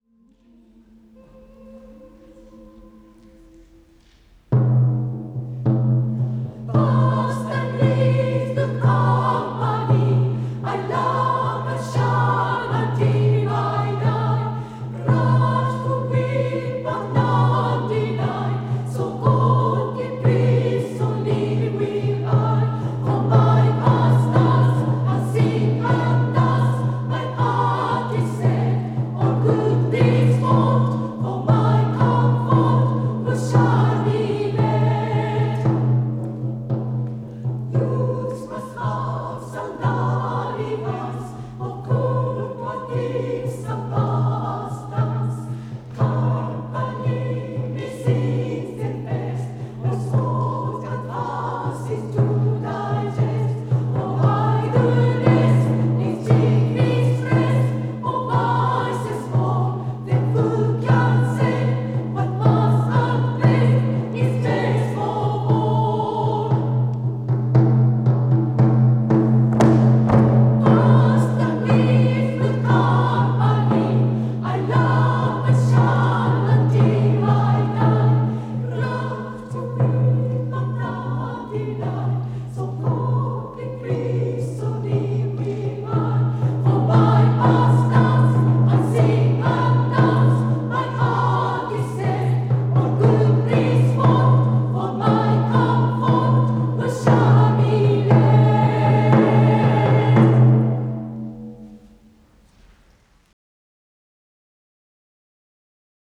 団員掲示板 | 女声アンサンブル クラルス・ヴォ―チェ